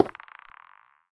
Minecraft Version Minecraft Version 1.21.5 Latest Release | Latest Snapshot 1.21.5 / assets / minecraft / sounds / block / lodestone / place4.ogg Compare With Compare With Latest Release | Latest Snapshot